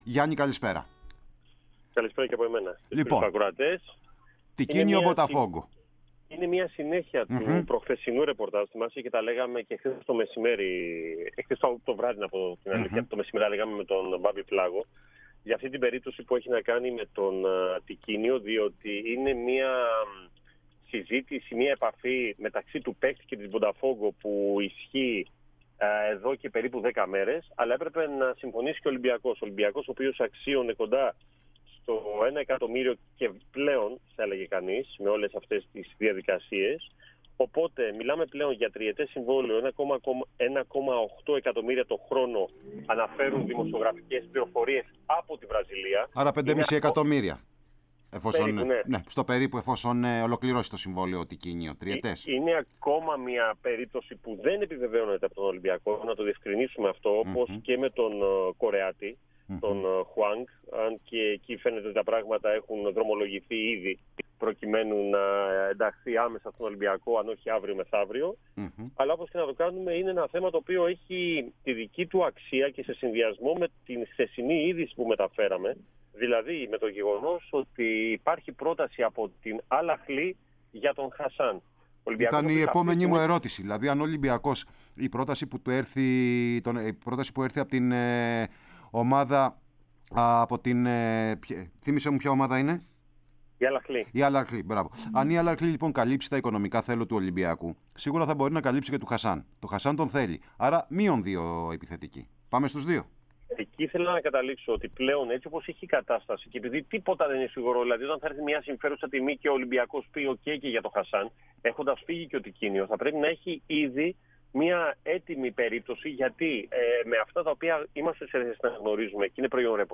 στον αέρα της ΕΡΑΣΠΟΡ